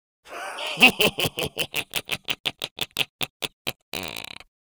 Sinister Laughs Male 01
Sinister Laughs Male 01.wav